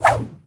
footswing4.ogg